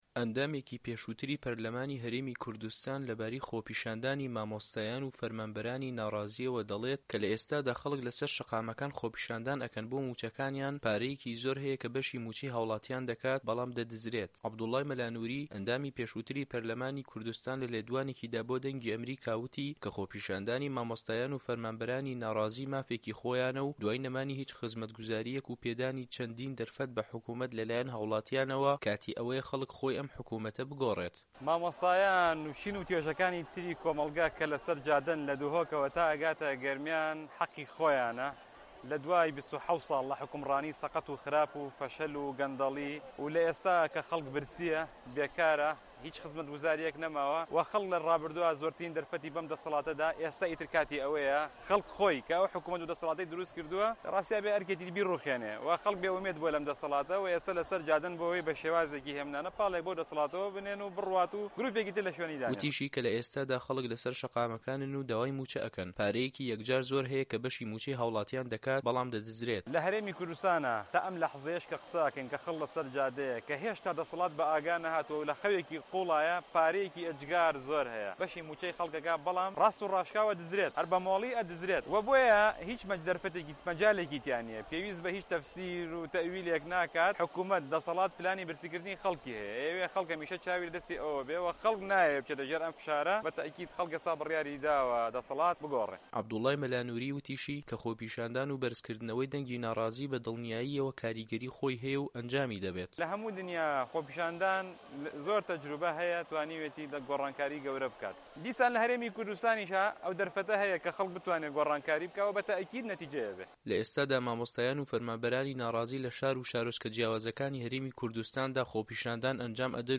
عه‌بدوڵای مه‌لا نووری، ئه‌ندامی پێشووتری په‌رله‌مانی کوردستان له‌ لێدوانێکیدا بۆ ده‌نگی ئه‌مه‌ریکا ده‌ڵێت که‌ خۆپیشاندانی مامۆستایان و فه‌رمانبه‌رانی ناڕازی مافێکی خۆیانه‌ و دوای نه‌مانی هیچ خزمه‌تگوزارییه‌ک و پێدانی چه‌ندین ده‌رفه‌ت به‌ حکومه‌ت له‌لایه‌ن هاوڵاتییانه‌وه‌، کاتی ئه‌وه‌یه‌ خه‌ڵک خۆی ئه‌م حکومه‌ته‌ بگۆڕێت.
ڕاپـۆرتی